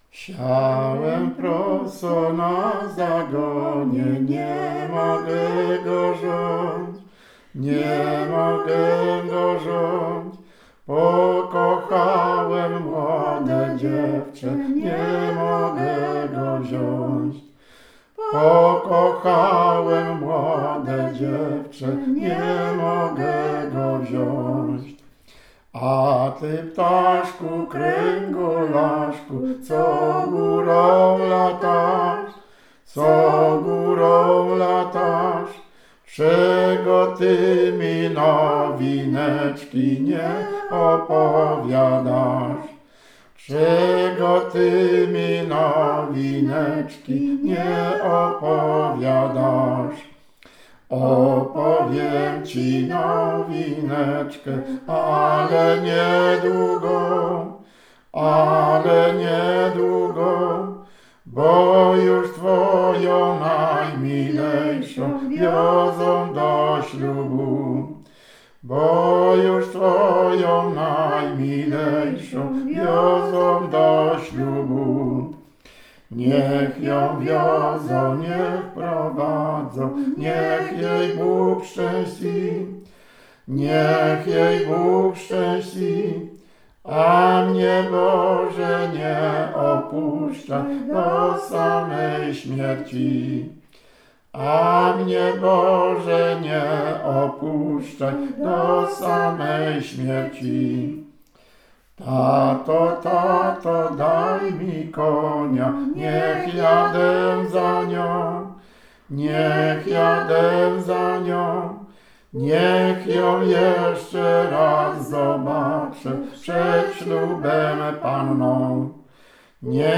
Utwór zarejestrowano w ramach nagrywania płyty "Od wschodu słońca... Tradycje muzyczne na Dolnym Śląsku" (Fundacja Ważka, 2014).